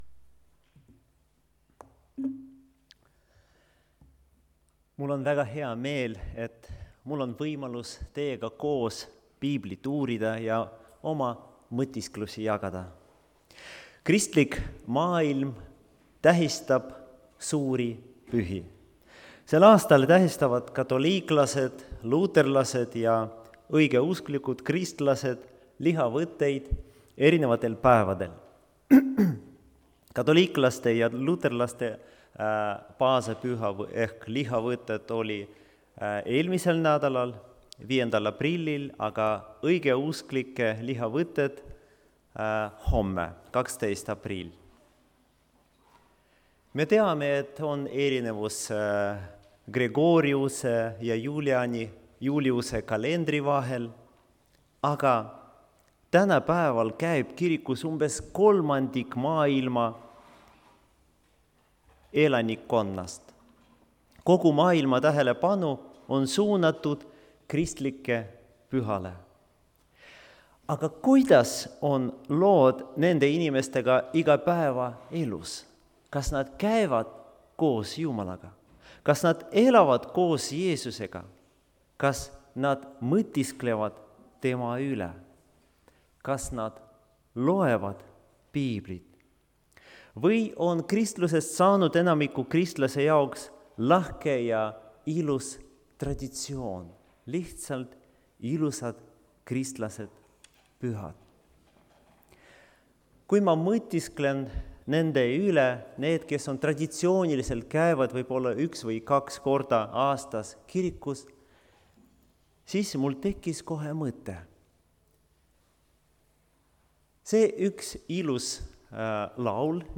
(Tallinnas)
Jutlused